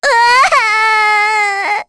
Rehartna-Vox_Sad3_kr.wav